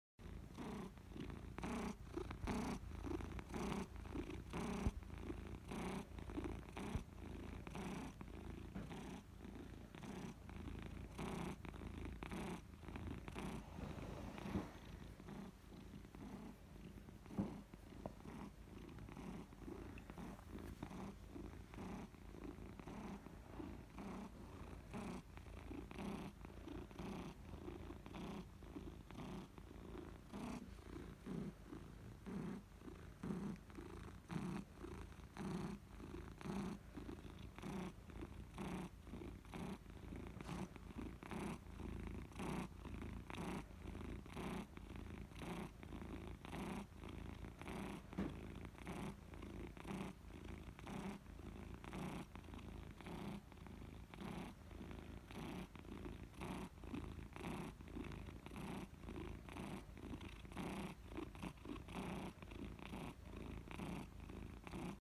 Звуки мурчания кошек
Нежно мурлычет